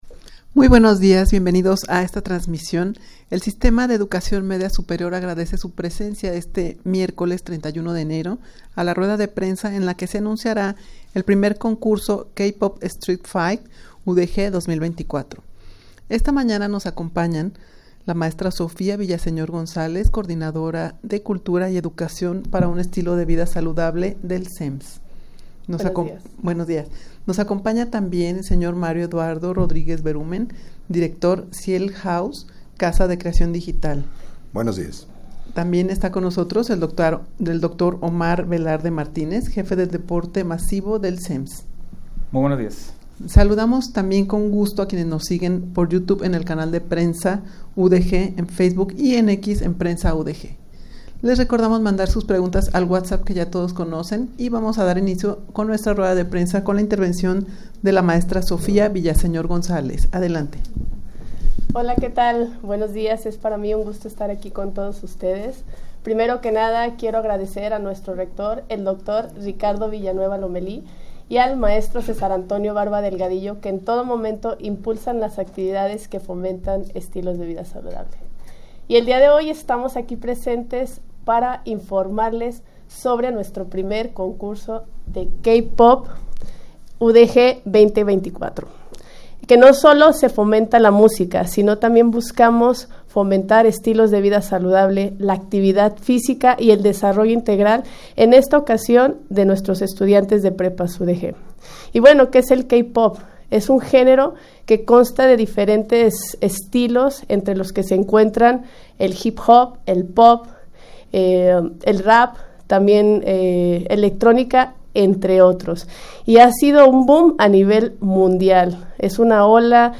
Audio de la Rueda de Prensa
rueda-de-prensa-en-la-que-se-anunciara-el-1er-concurso-k-pop-street-fight-udeg-2024.mp3